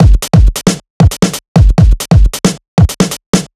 Toshi Break 135.wav